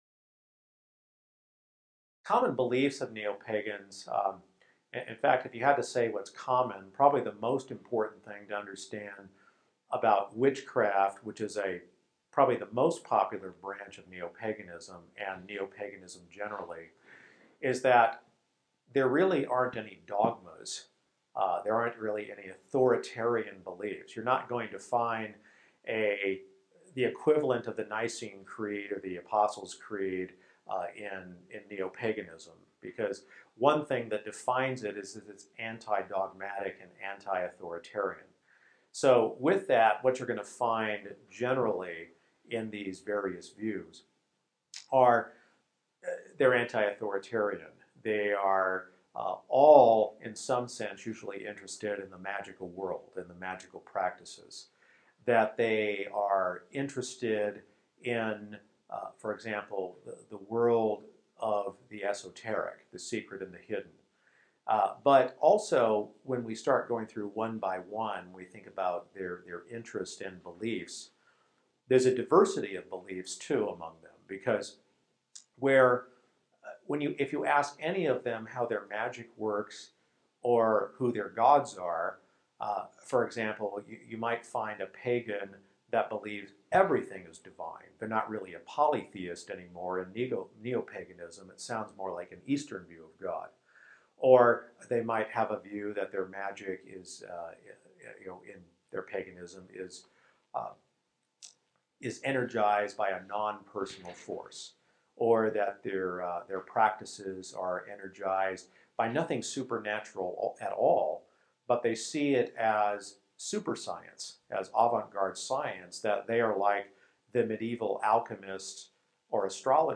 ELF Interviews